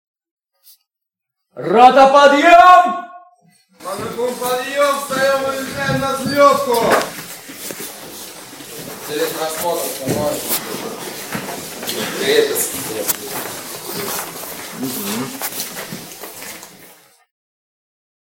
Эти треки воспроизводят настоящие горны и трубы, используемые для утреннего подъёма солдат.
Рота подъем - армейский сигнал для будильника